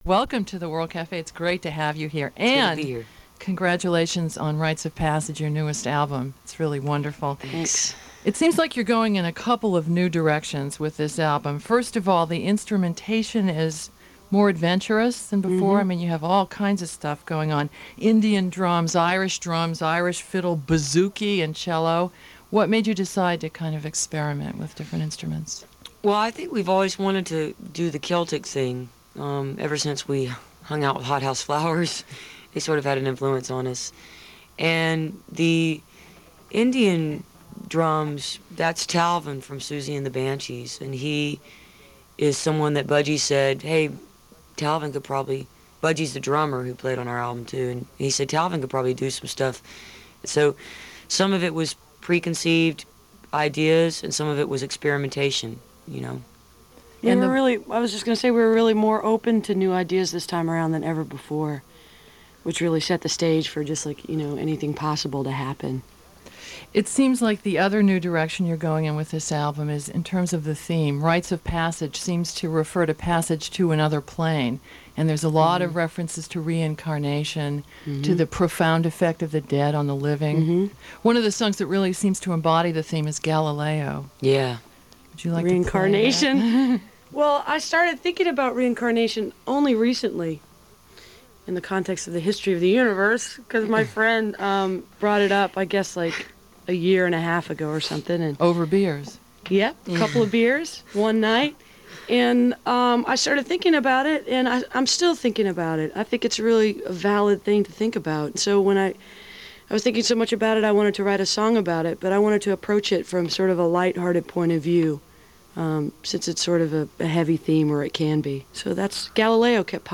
lifeblood: bootlegs: 1992-06-26: world cafe radio show
01. interview (2:09)